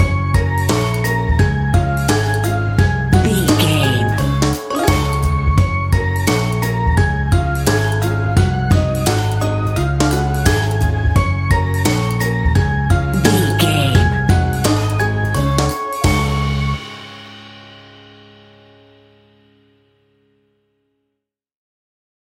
Ionian/Major
D♭
instrumentals
fun
childlike
cute
happy
kids piano